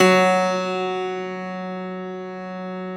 53e-pno08-F1.wav